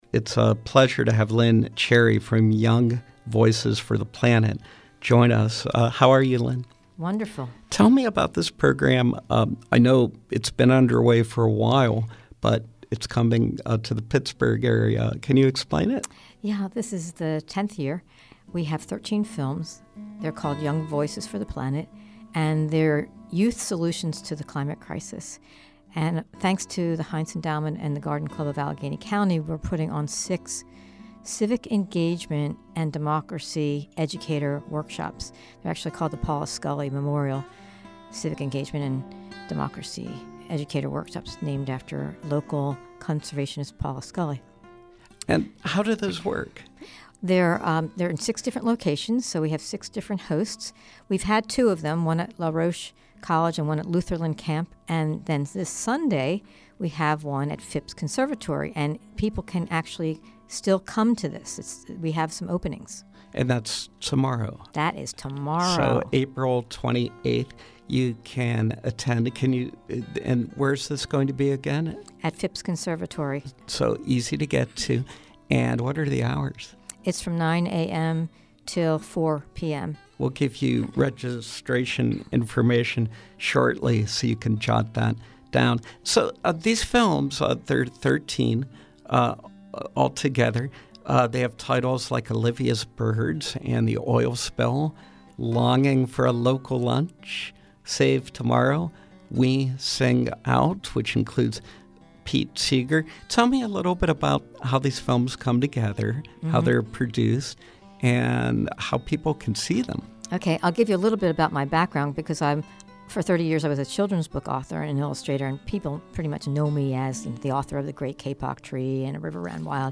In Studio Pop-Up: Young Voices for the Planet
Interviews